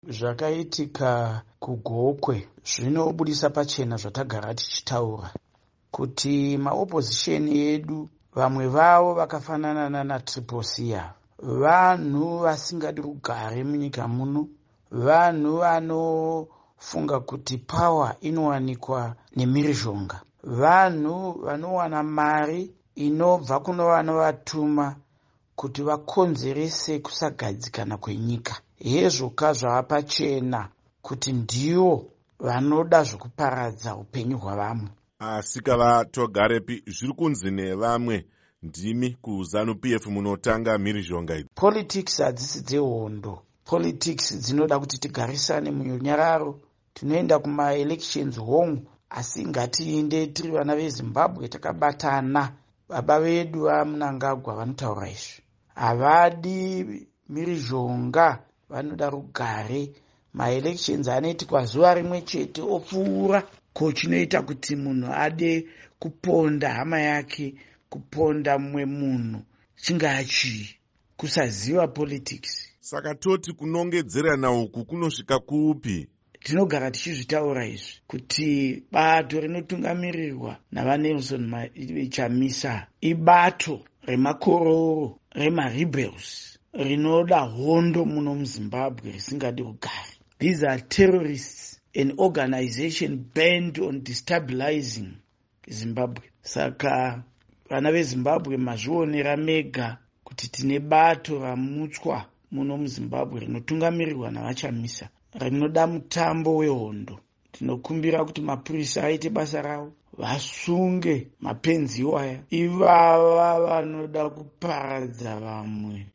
Hurukuro naVaPupurai Togarepi